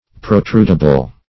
Protrudable \Pro*trud"a*ble\, a. That may be protruded; protrusile.
protrudable.mp3